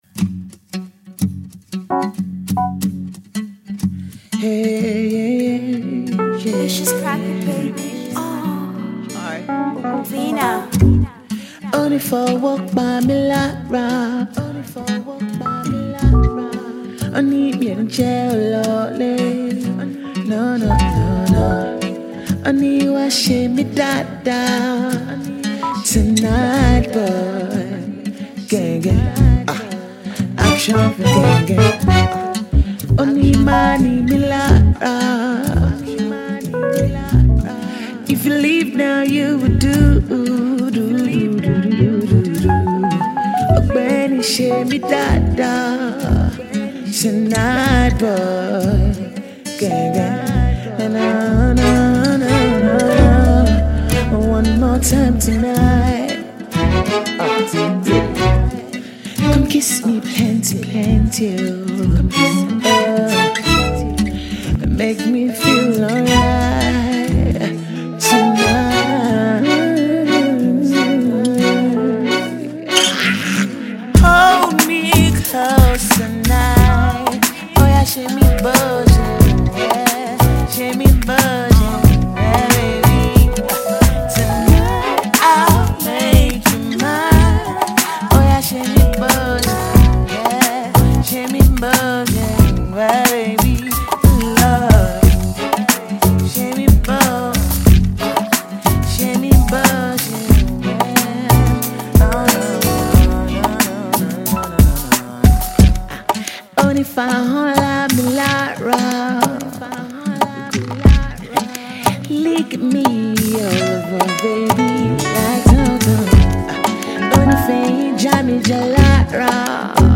Splendid vocals
soulful and jazzy production, African drums…